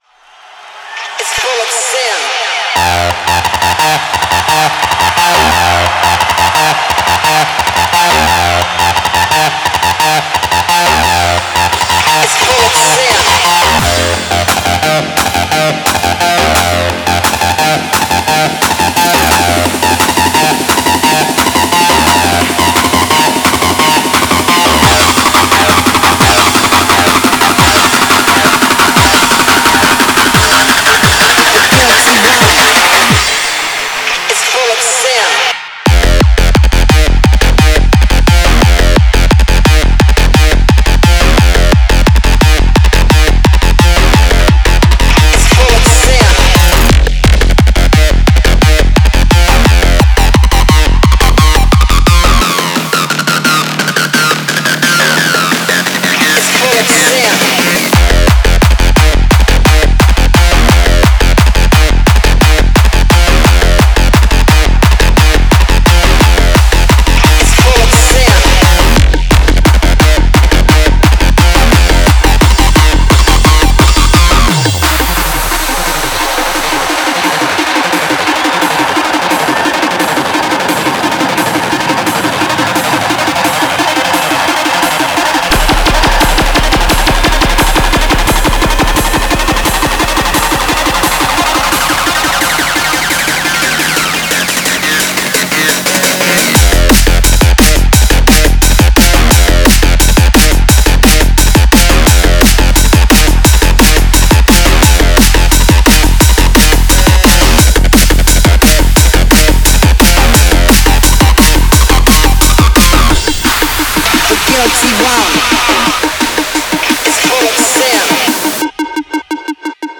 BPM174
Audio QualityMusic Cut